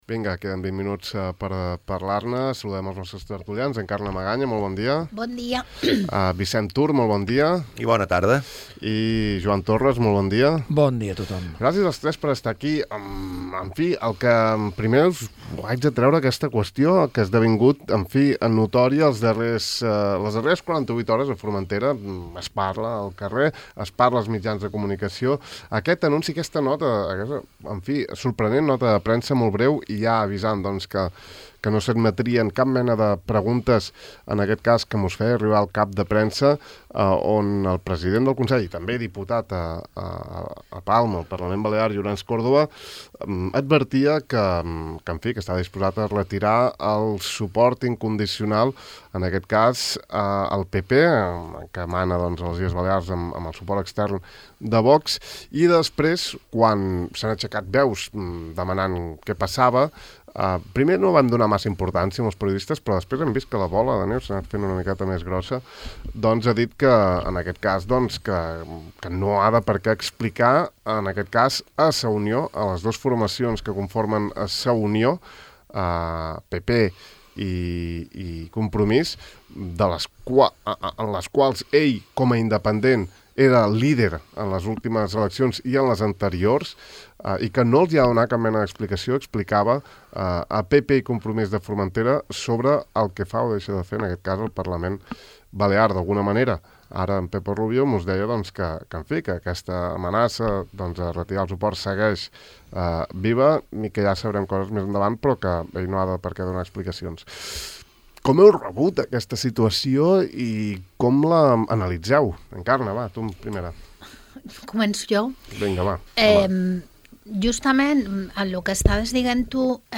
Tertúlia ciutadana: els participants opinen sobre les declaracions de Córdoba i el cas del vicepresident del GOIB Costa
Cada dia acabem el De far a far donant veu a la ciutadania de Formentera en una tertúlia on tothom hi és benvingut.